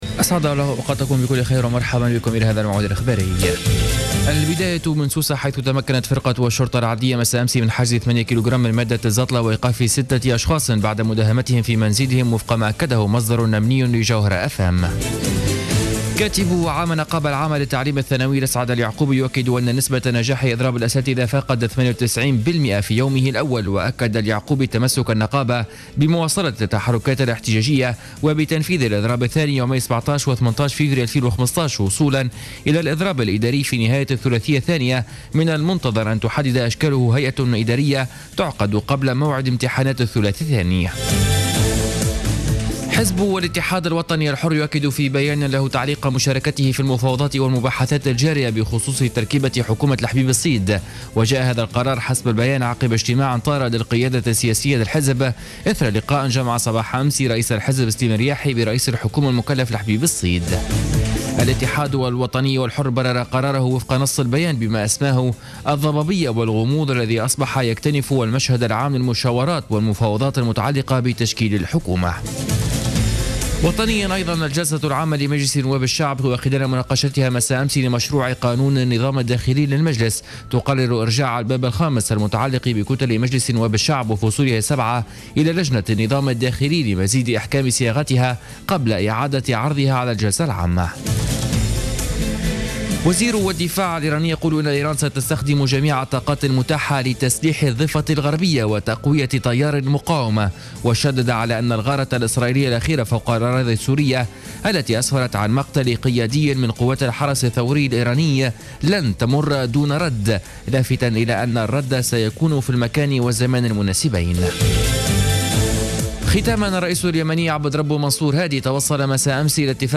نشرة أخبار منتصف الليل ليوم الخميس 22 جانفي 2014